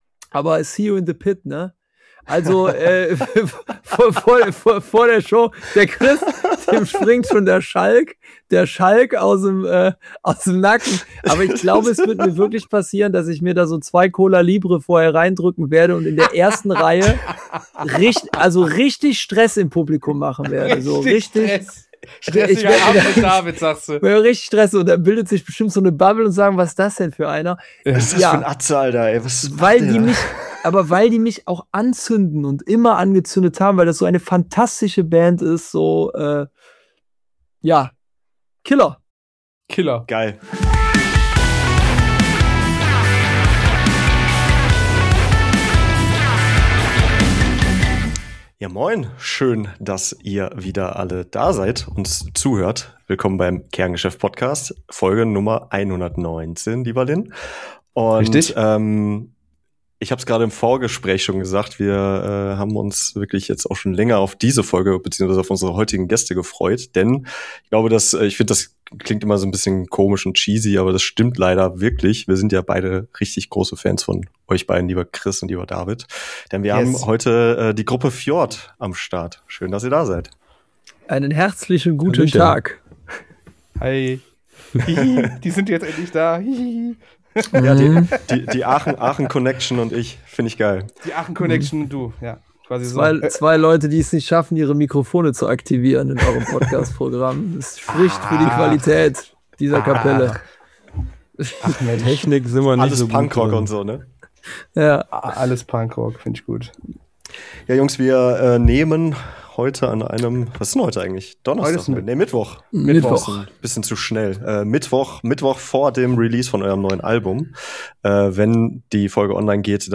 Ein Talk über Subkulturen und Bring Me The Horizon, Rechtsruck und Positionierung, Sprachbarrieren und Authentizität.